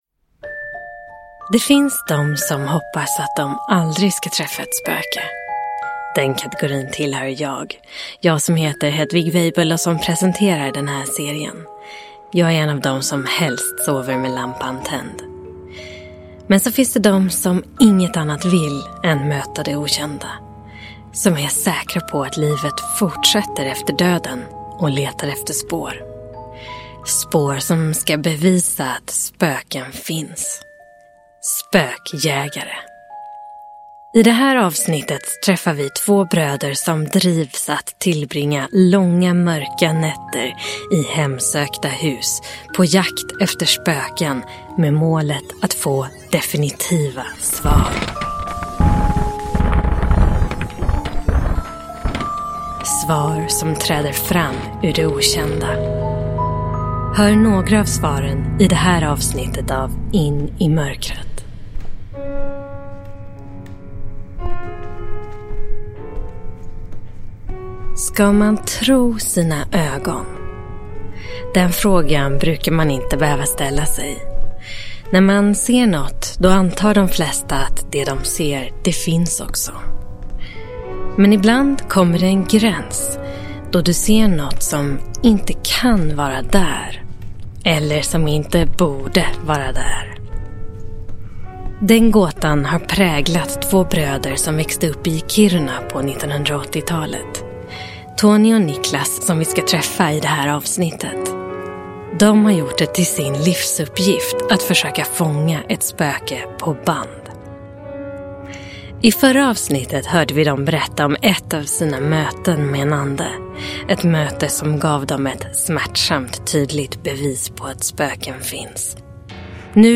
Spökhistorier på riktigt. Del 4 – Ljudbok – Laddas ner